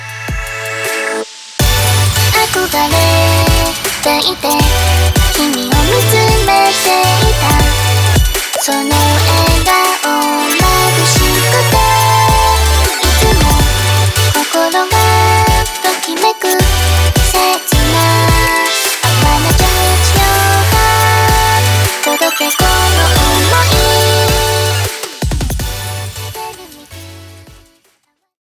FutureBase